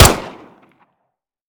Home gmod sound weapons papa320
weap_papa320_fire_plr_01.ogg